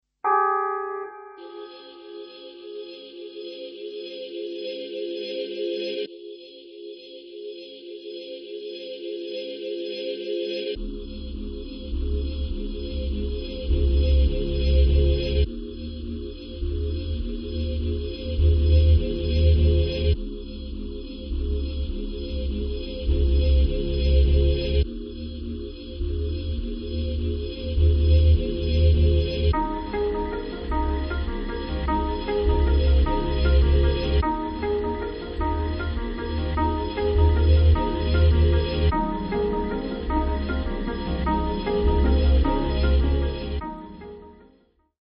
Guitar
relaxed slow voc.